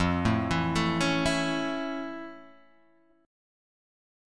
F+M7 chord